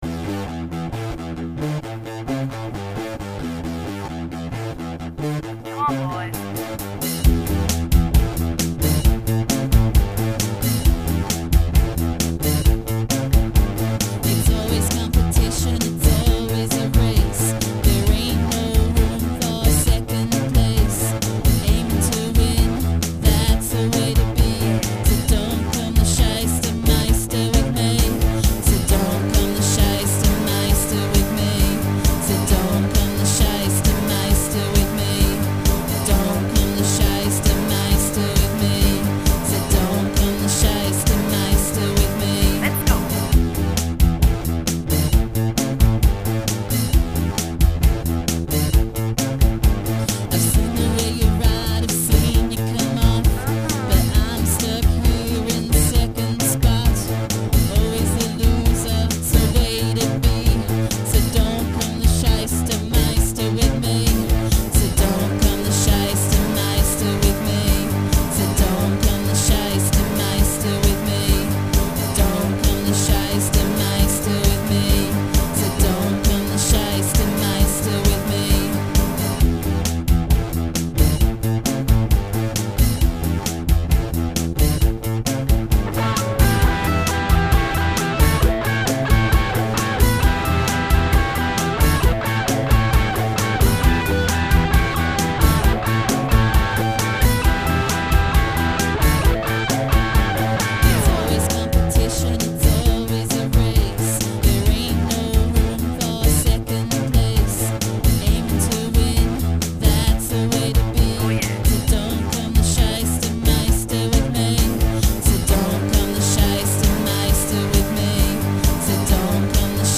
Have a listen to these badly mixed songs I wrote - using Impulse Tracker, my electric guitar, bass and some vocals.